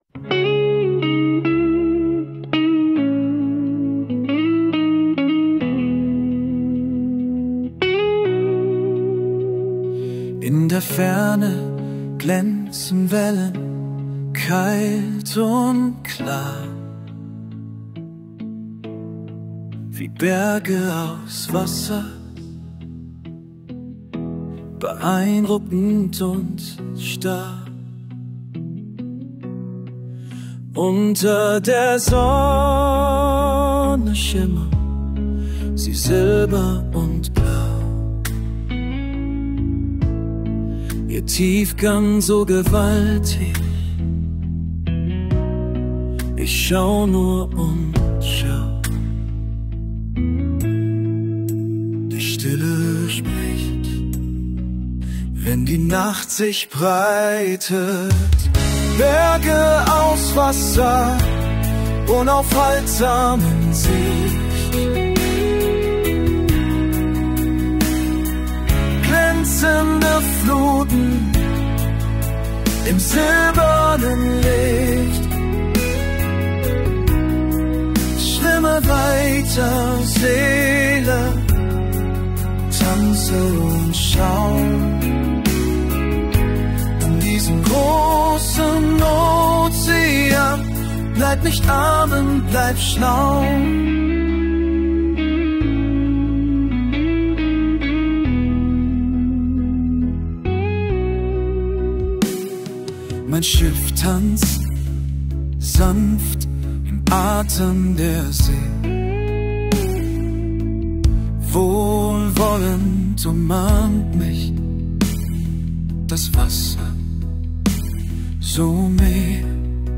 Berge aus Wasser (Musik)